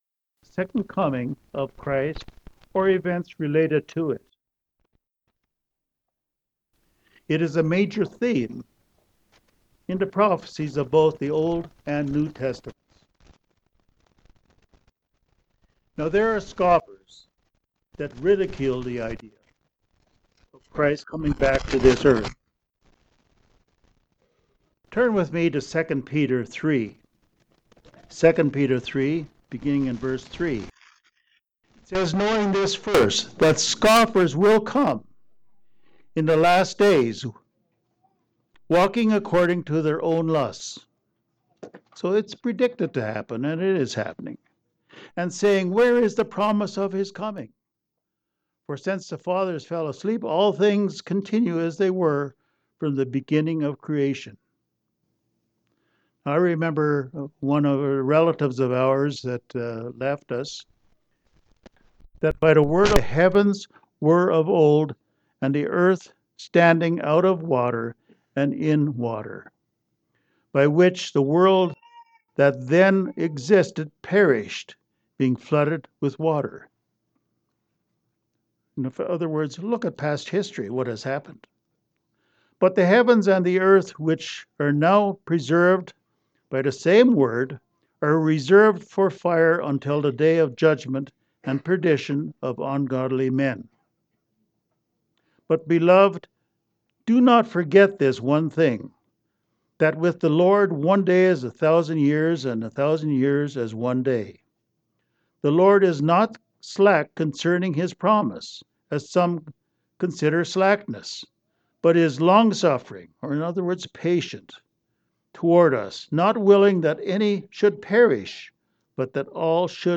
This sermon clearly discusses why Christ must return even though scoffers say otherwise.
Given in Denver, CO